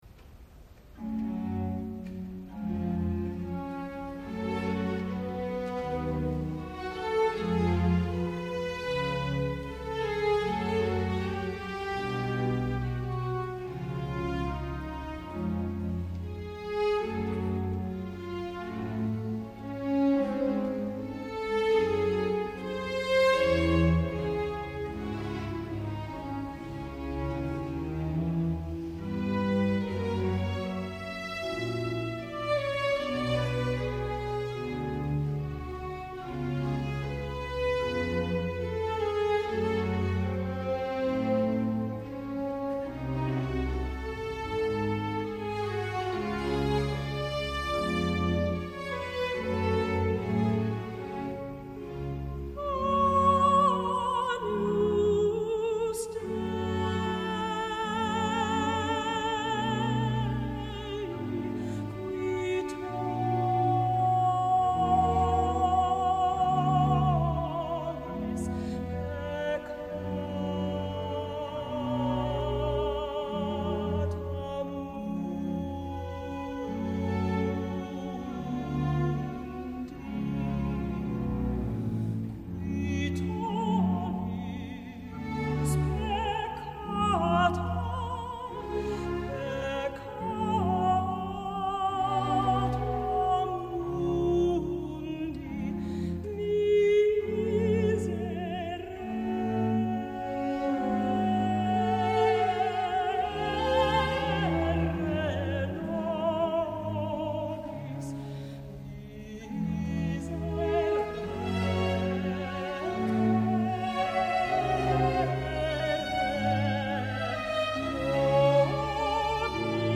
atenció al silenci abans dels primers aplaudiments!
Solistes vocals, membres del cor Monteverdi
Kaiserdom, Königslutter Alemanya.